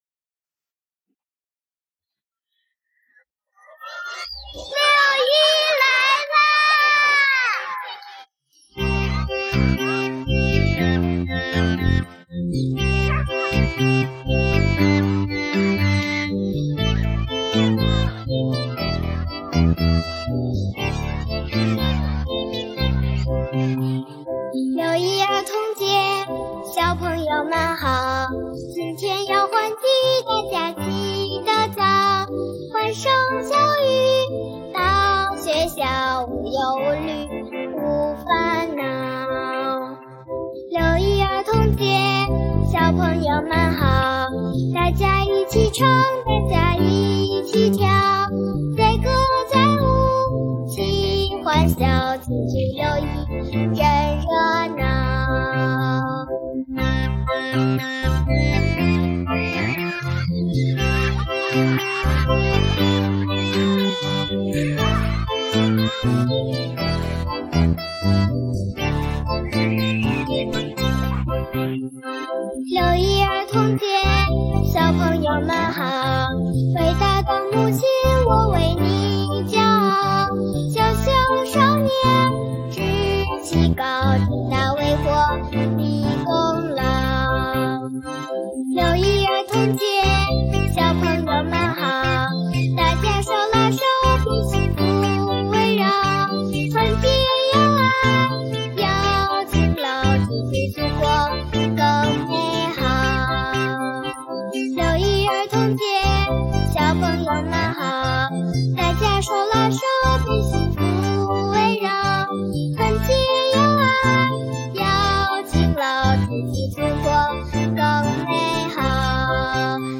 儿童歌曲